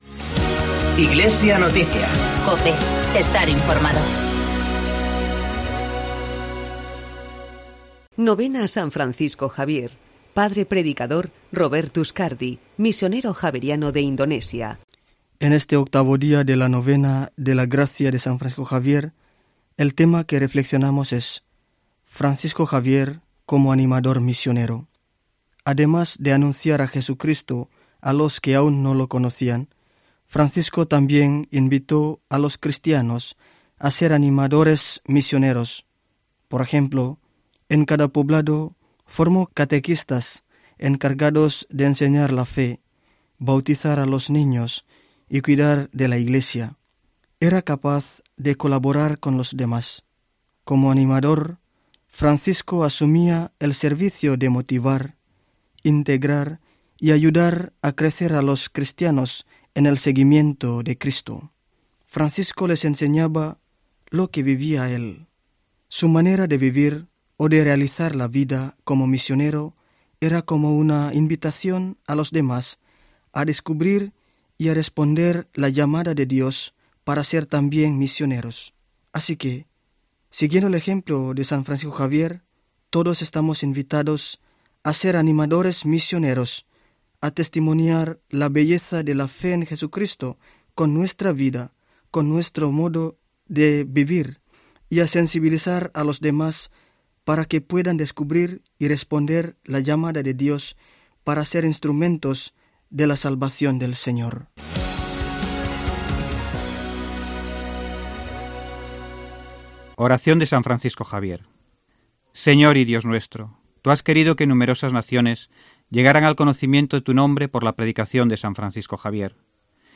Padre predicador